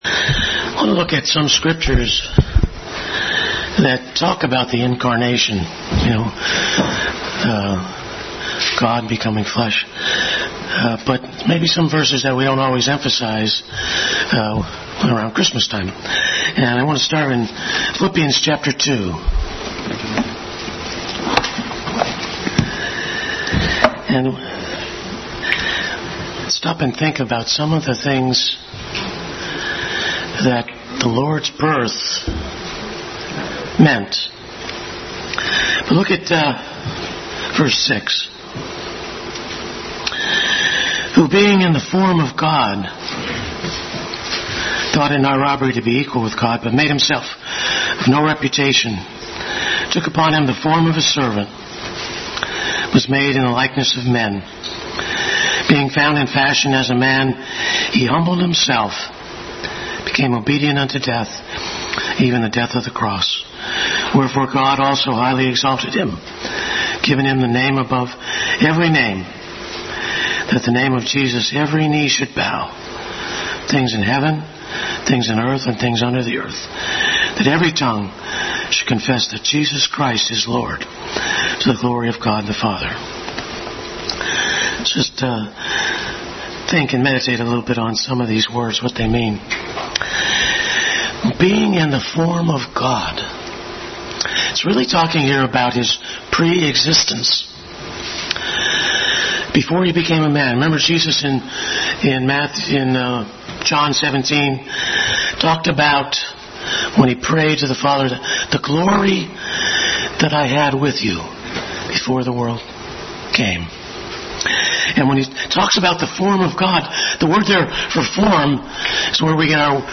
Bible Text: Phlippians 2:6-11, 2 Corinthians 8:9, Galatians 4:4-7, 1 Timothy 3:16, John 1:14, Isaiah 9:6-7, Romans 1:3 | Adult Sunday School. A study of various scriptures concerning the incarnation of our Savior.